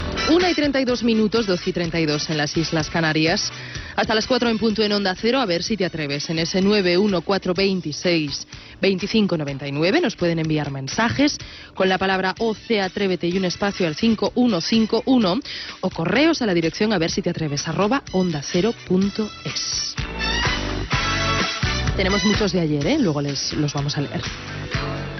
Hora, inici del programa de matinada. Invitació als oients a participar.
Entreteniment